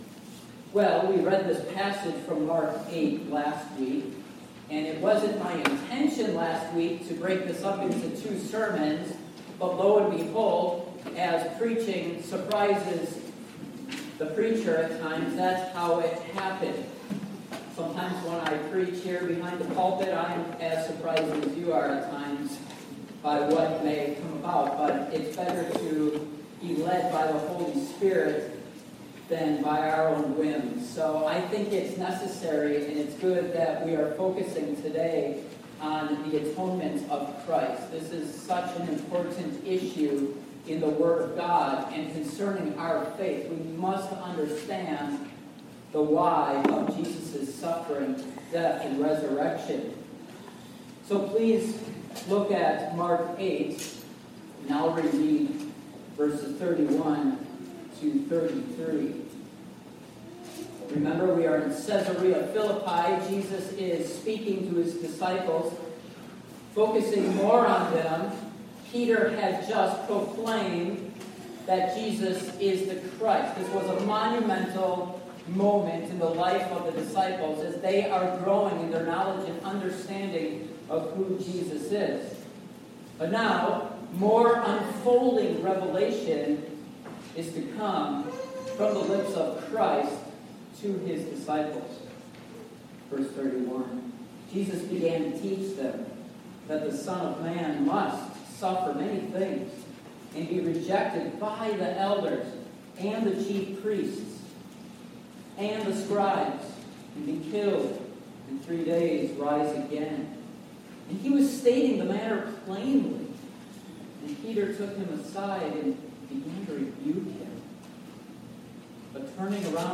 Passage: Mark 8:31-33 Service Type: Morning Worship